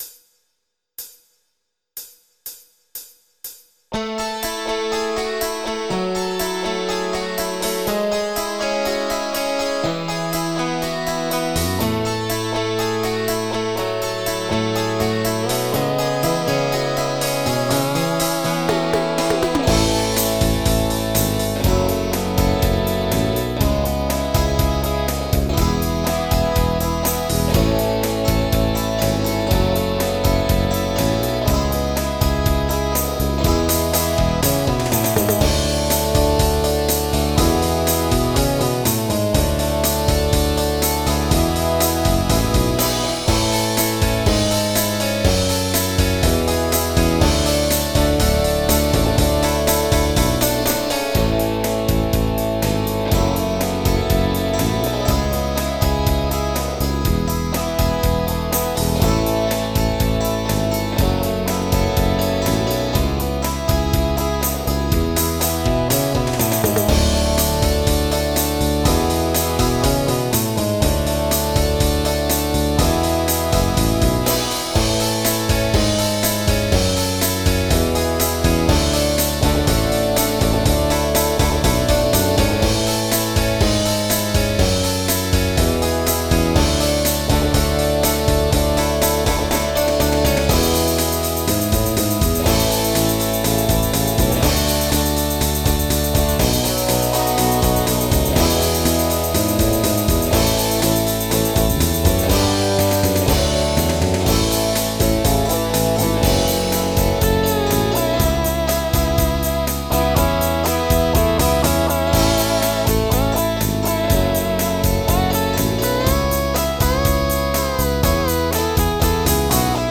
Oldies
MIDI Music File